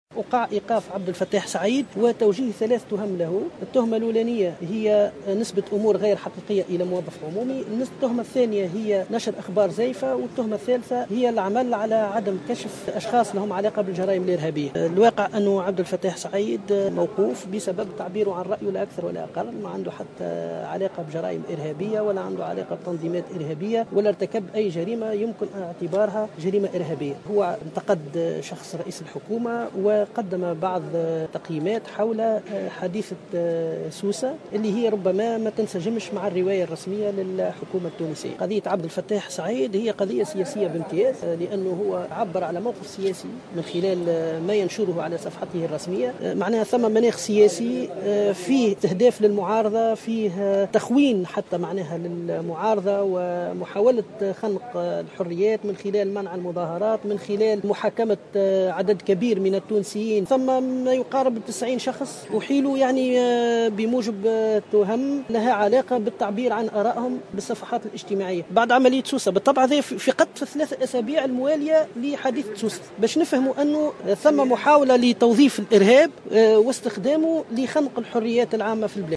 في تصريح لمراسل الجوهرة أف أم